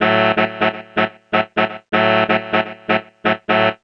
cch_synth_junes_125_A.wav